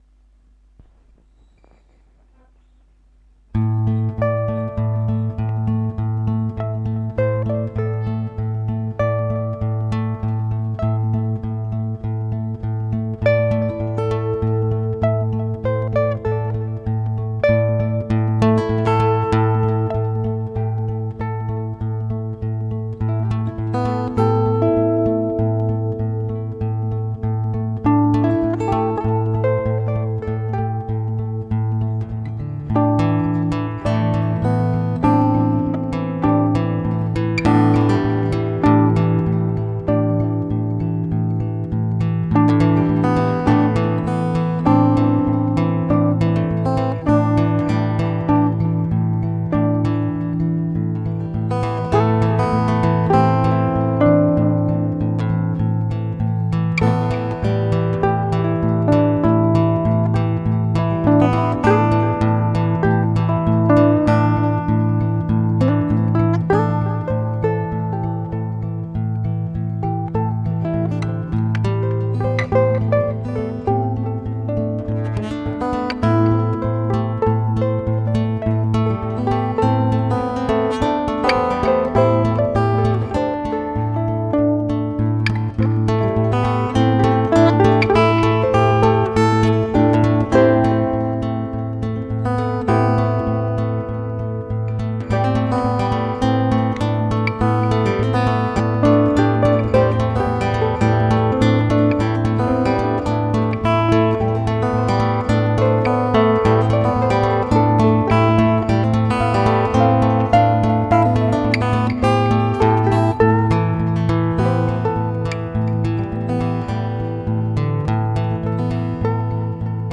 Gitarre/Ukulele